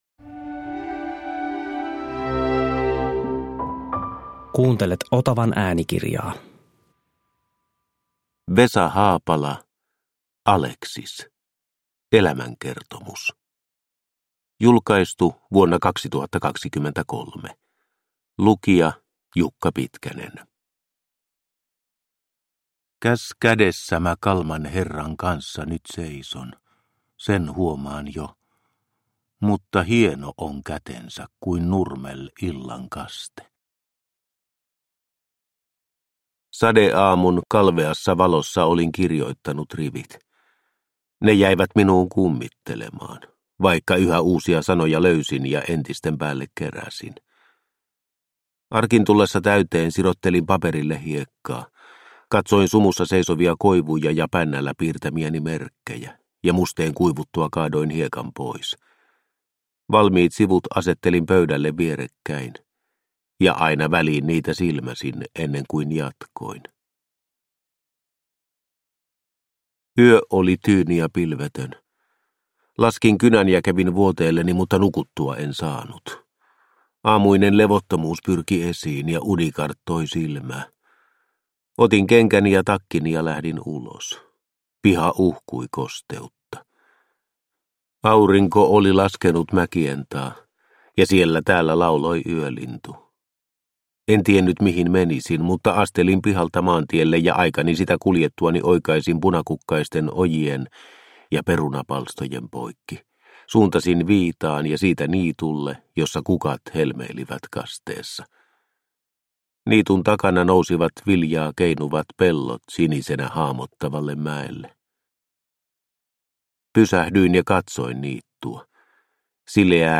Alexis – Ljudbok – Laddas ner
Produkttyp: Digitala böcker